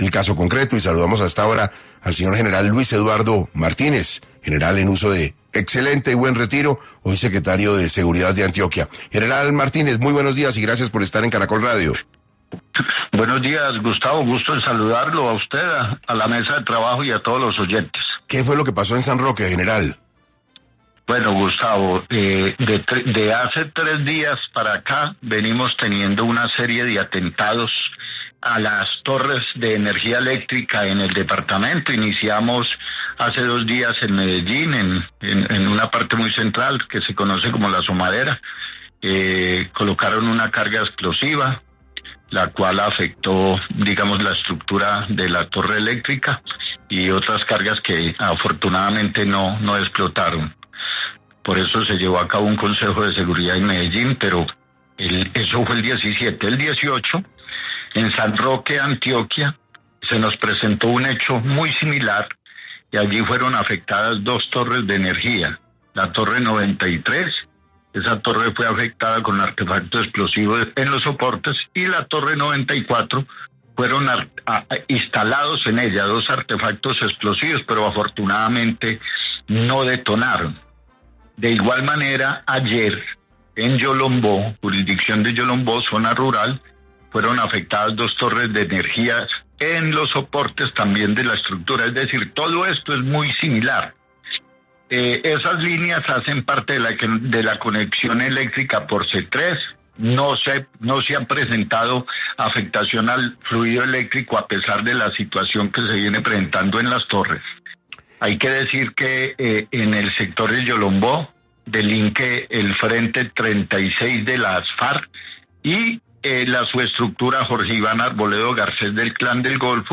Luis Eduardo Martínez, Secretario de Seguridad de Antioquia, hizo un llamado de atención respecto a la situación de orden público en el departamento
Su secretario de seguridad, Luis Eduardo Martínez, dio un reporte detallado de la situación.